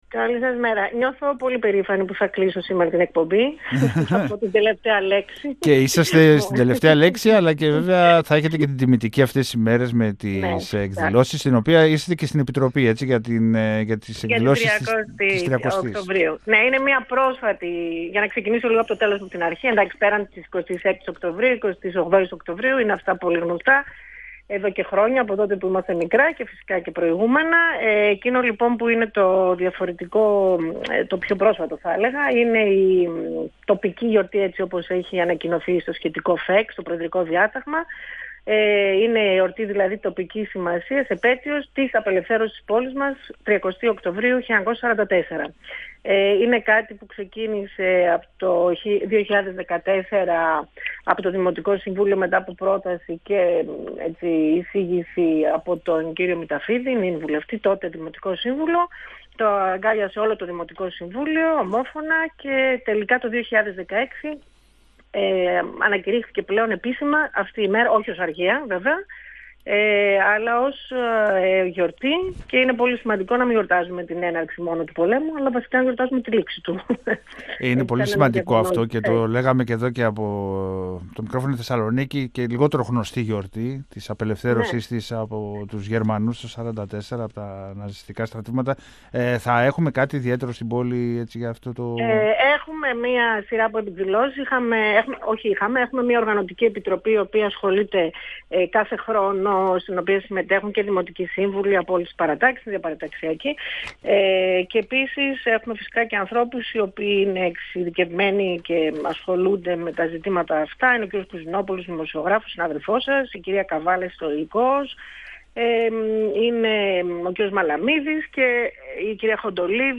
Η πρόεδρος του Δημοτικού Συμβουλίου Θεσσαλονίκης, Καλυψώ Γούλα, στον 102FM του Ρ.Σ.Μ. της ΕΡΤ3
Για τις εκδηλώσεις μίλησε στον 102FM της ΕΡΤ3 η πρόεδρος του Δημοτικού Συμβουλίου Θεσσαλονίκης, Καλυψώ Γούλα.